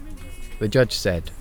noisy-speech-files